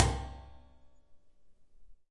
呻吟声、呻吟声和战斗的呼喊声 " 00928人被打的尖叫声8
描述：人打架喊话的强音由AKG C4141 TLII
标签： 咆哮 命中 男子 尖叫 呼喊 呼呼
声道立体声